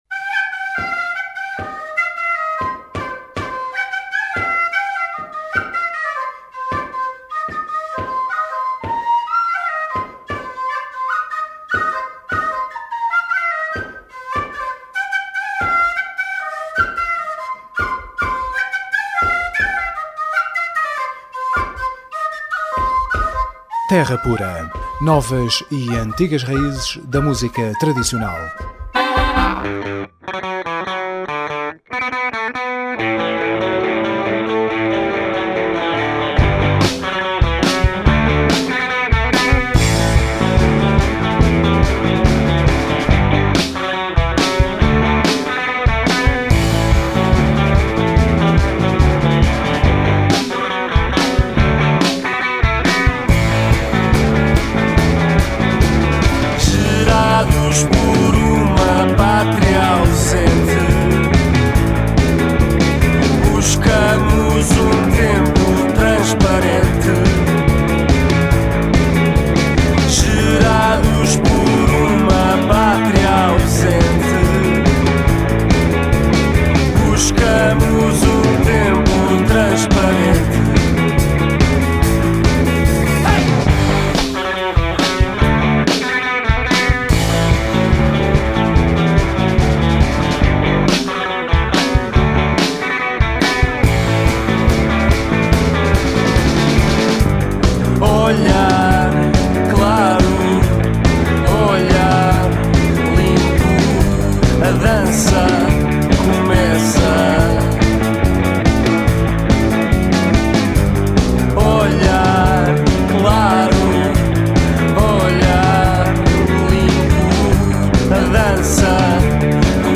Terra Pura 04JUL11: Entrevista Os Golpes – Crónicas da Terra